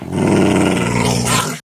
wolf_growl3.ogg